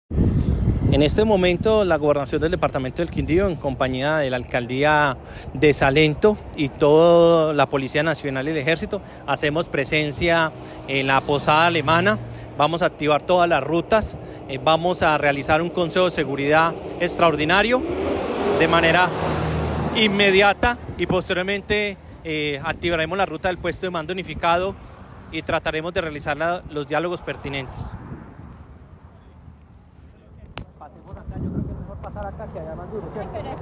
Jaime Andrés Pérez, secretario del interior del Quindío